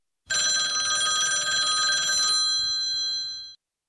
Phone ringgg
phone_9hX33kP.mp3